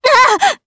Ripine-Vox_Damage_jp_03.wav